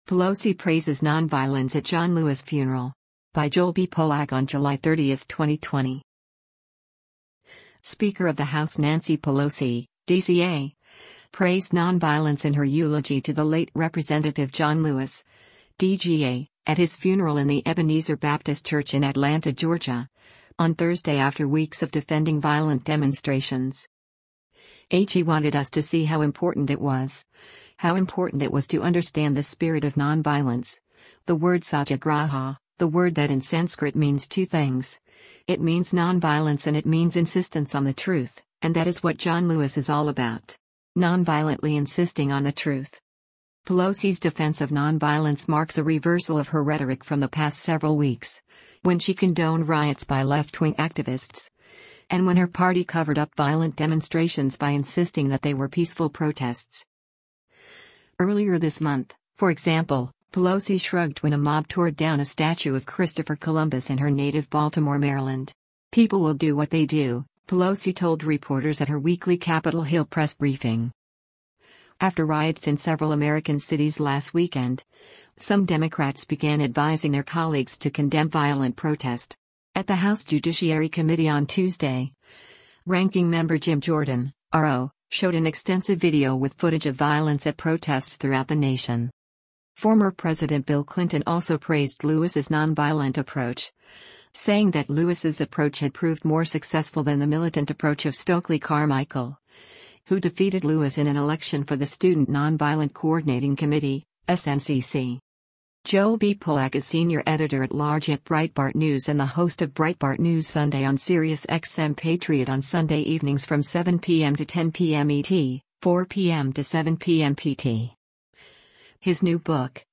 Speaker of the House Nancy Pelosi (D-CA) praised non-violence in her eulogy to the late Rep. John Lewis (D-GA) at his funeral in the Ebenezer Baptist Church in Atlanta, Georgia, on Thursday after weeks of defending violent demonstrations.